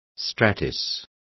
Complete with pronunciation of the translation of stratus.